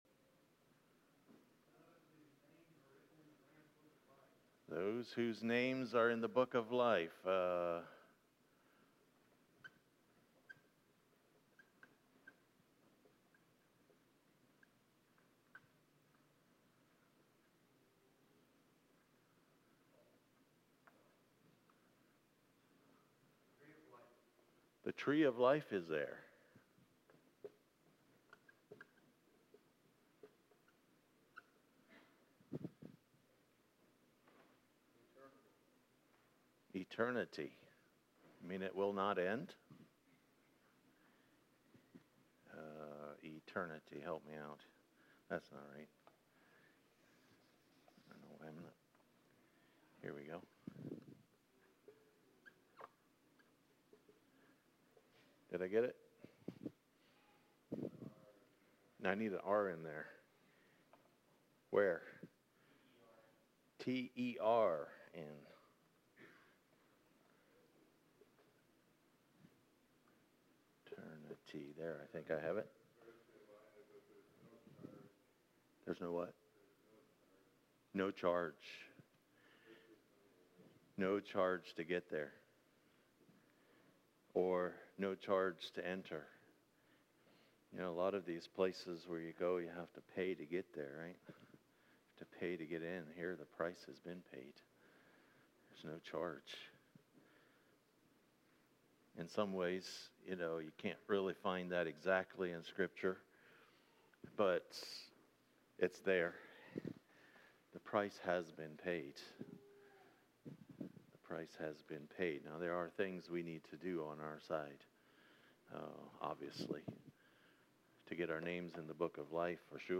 *There was a malfunction in recording this sermon, consequently the first few minutes were lost.